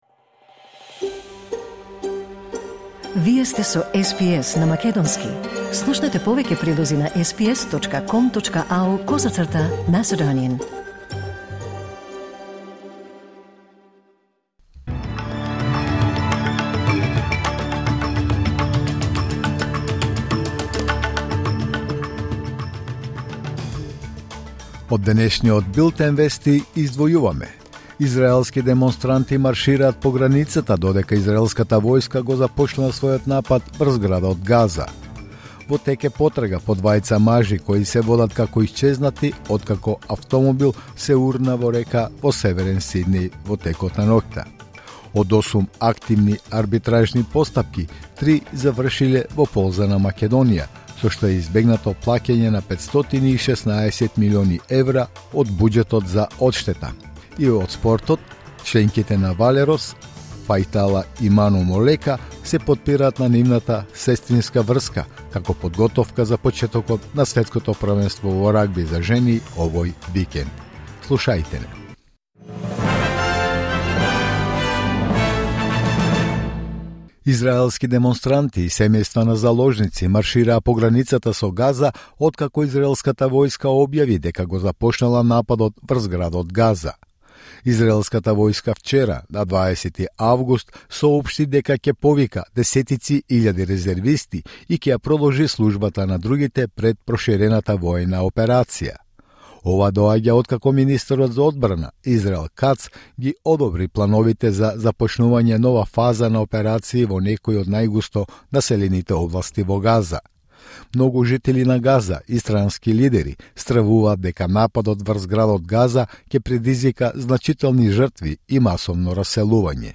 Вести на СБС на македонски 21 август 2025